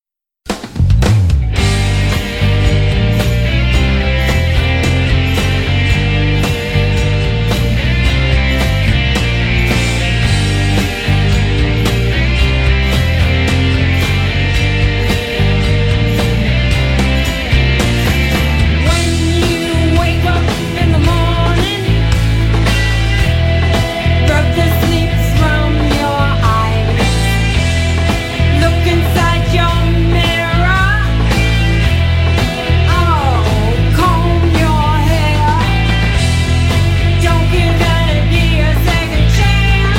The musicianship is a powerhouse classic rock line up, funky